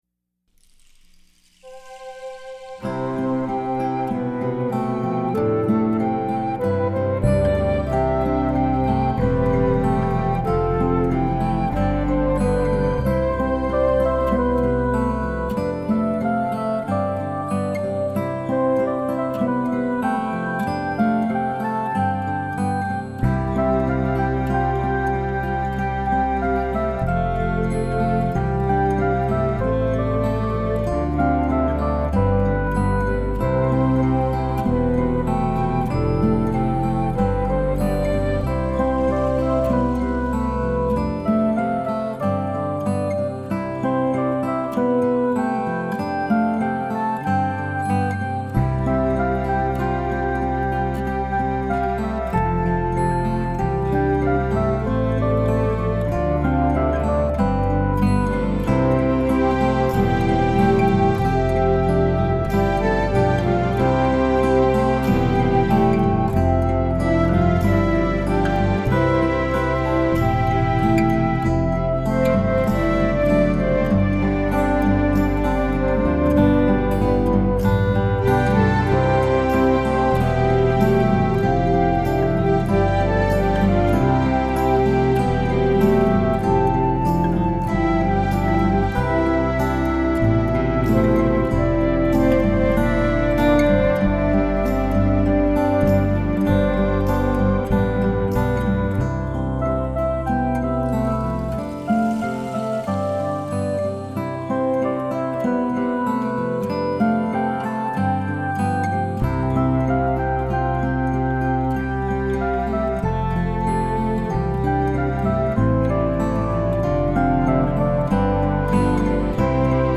Let these instrumentals take you on a musical journey of healing with their touching melodies.
Later on, I gravitated more to acoustic guitar, piano and piano, and solo piano.